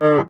animalia_cow_hurt.ogg